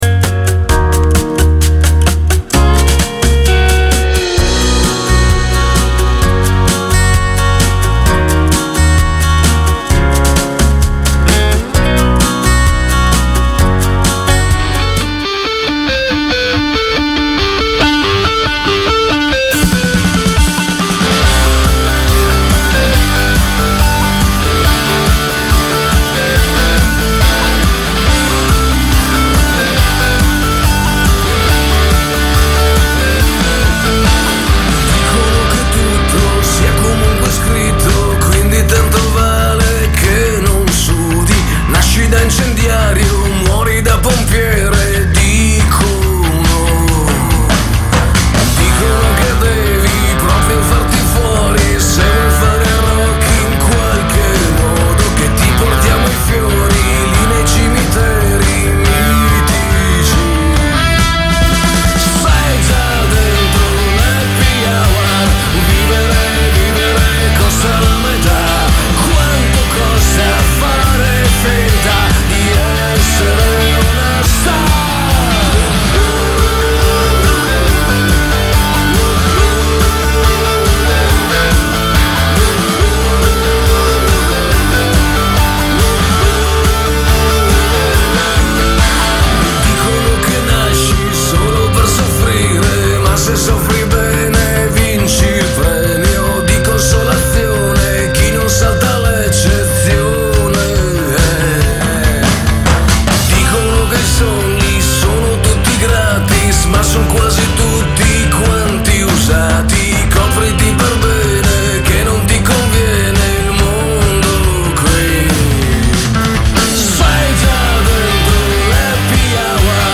RUBRICA SULLA SICUREZZA QUINTA PUNTATA SU RADIO DREAM ON FLY.